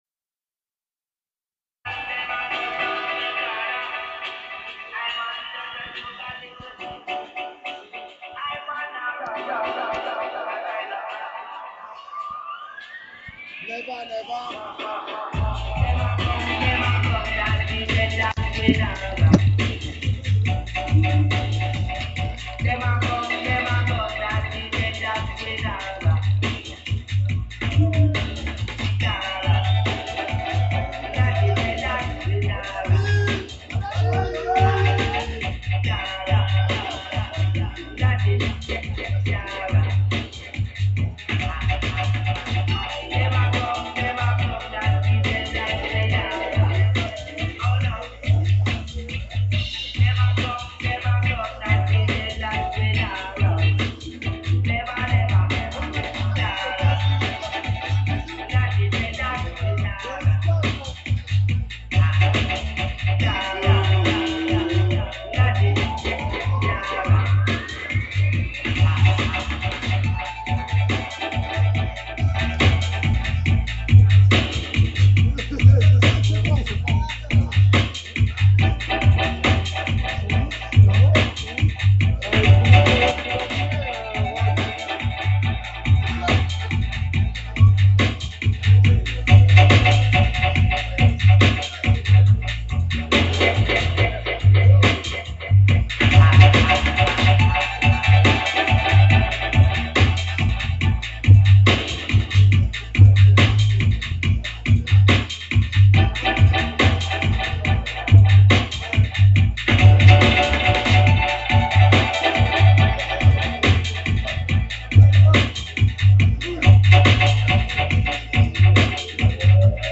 LIVE @ PARIS HYPER LOURD !!!!